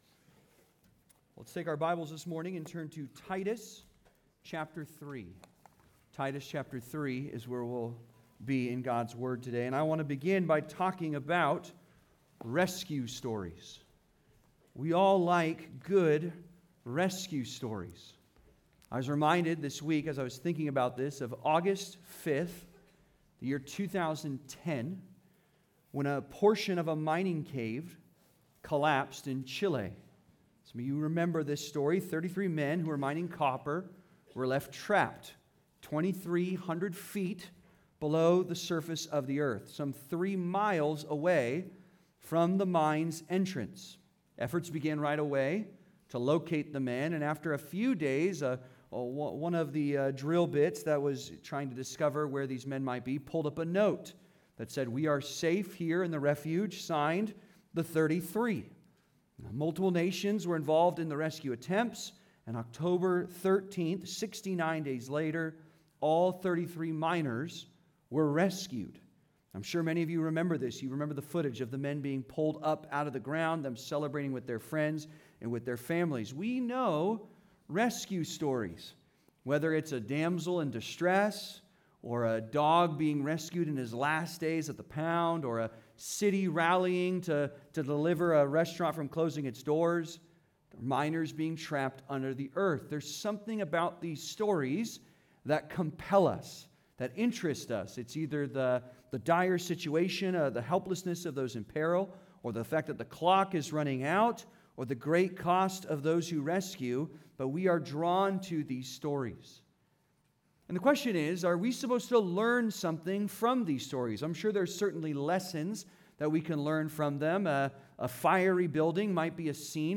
How Great A Salvation (Sermon) - Compass Bible Church Long Beach